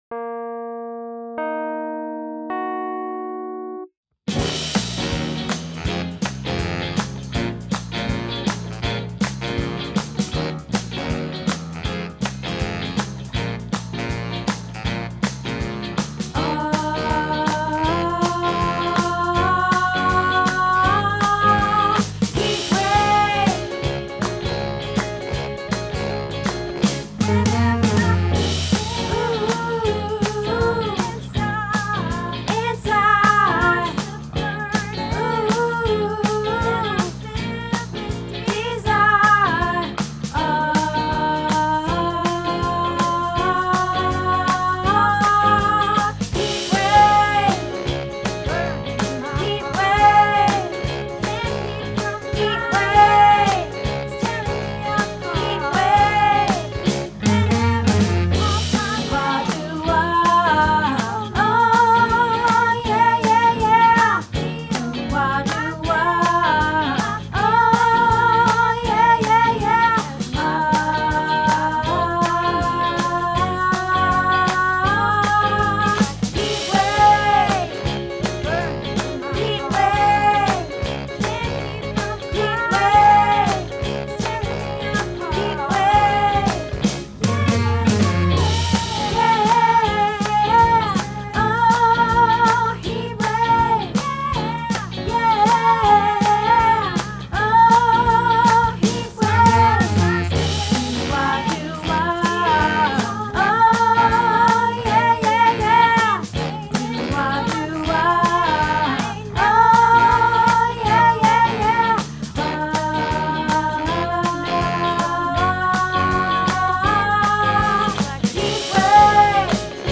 Heatwave - Tenor